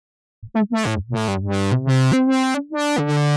Index of /musicradar/uk-garage-samples/142bpm Lines n Loops/Synths